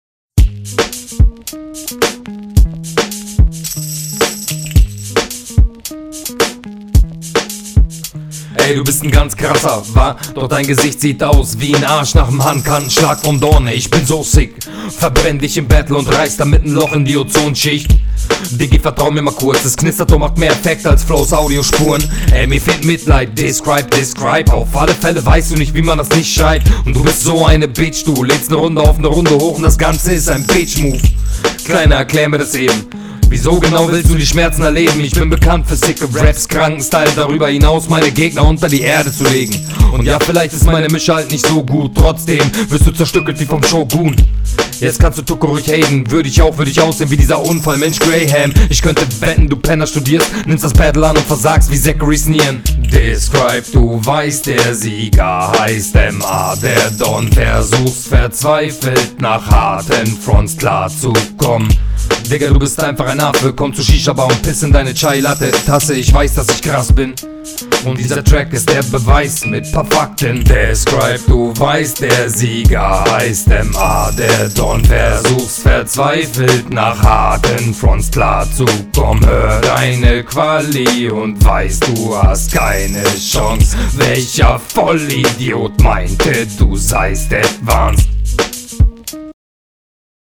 Sound ist ganz cool, du flowst routiniert und on point. find deine Betonungen teils etwas …